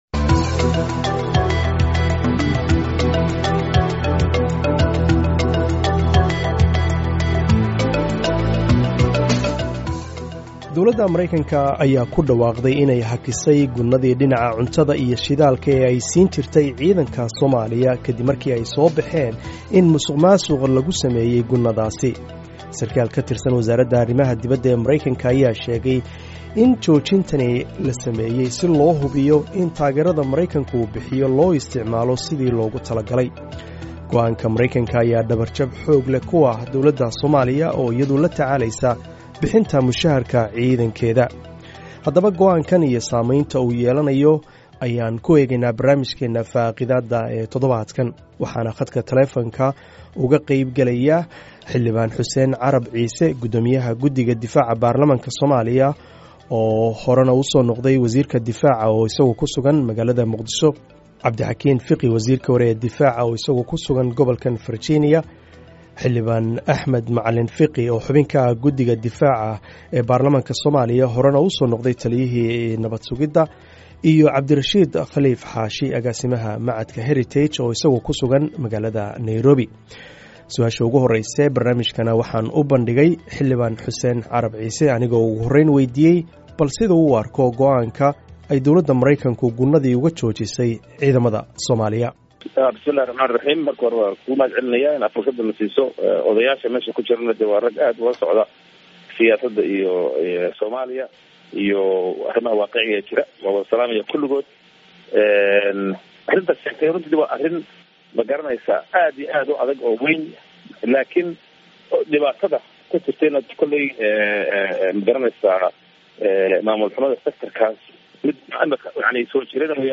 Halkaan ka dhageyso Dooda oo dhameystiran